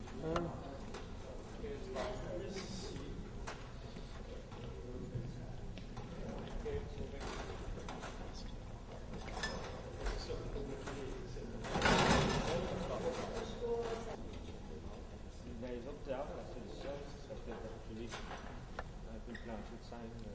随机 " 剧院工作人员的舞台设置 魁北克语和普通话的声音1
标签： 魁北克 船员 柑橘 声音 设置 戏剧
声道立体声